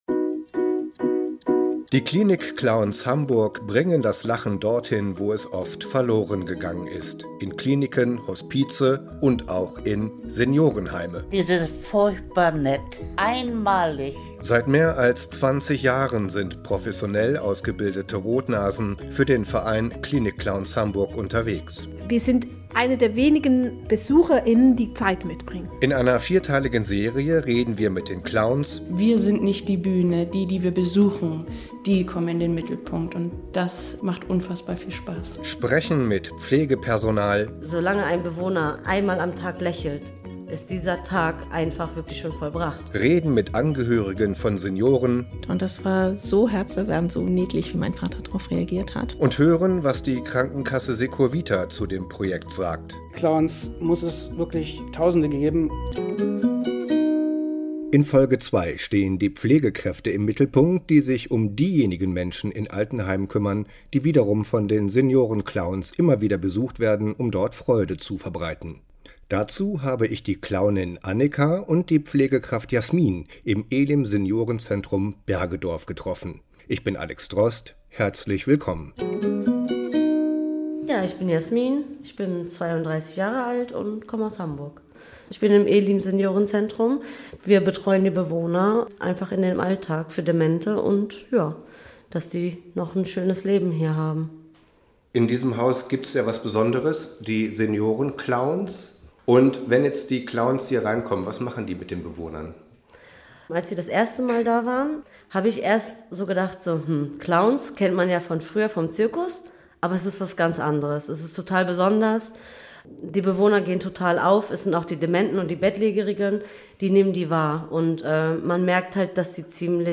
In der zweiten Folge erzählen Pflegekräfte, wie Pflege und Seniorenclowns „Hand in Hand“ zusammenarbeiten und was man voneinander lernen kann.